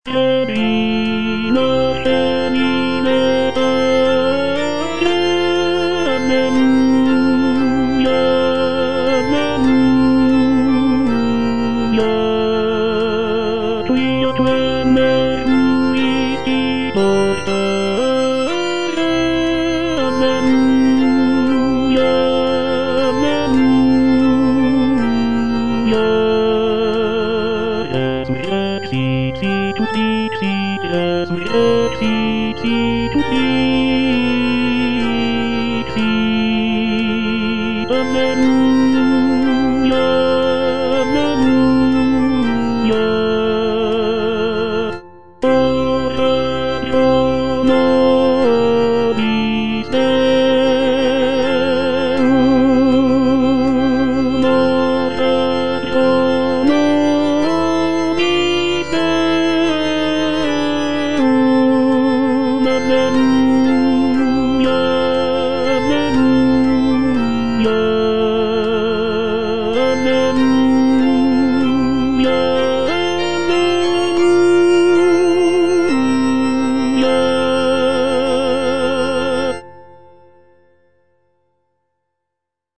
Répétition SATB4 par voix
Ténor